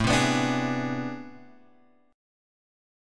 AmM11 chord {5 3 x 4 3 4} chord